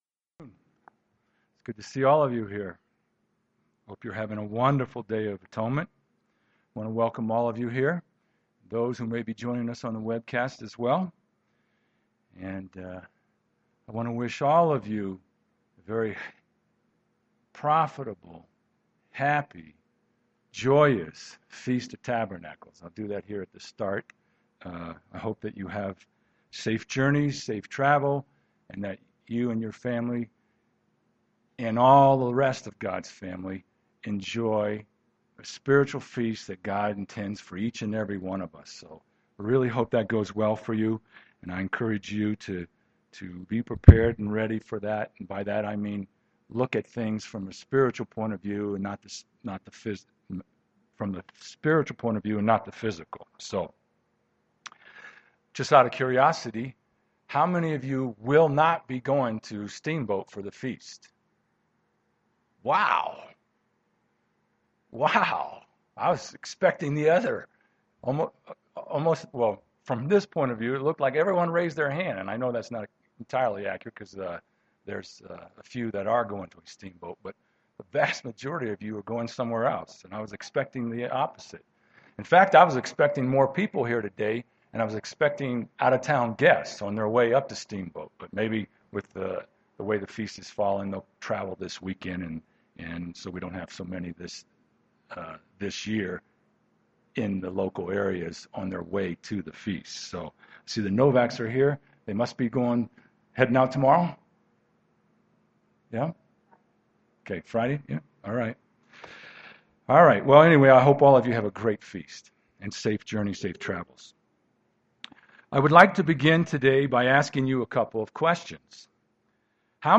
Given in Denver, CO
UCG Sermon Studying the bible?